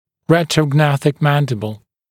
[ˌretrə(u)ˈgnæθɪk ‘mændɪbl][ˌрэтро(у)’гнэсик ‘мэндибл]нижняя челюсть в ретропозиции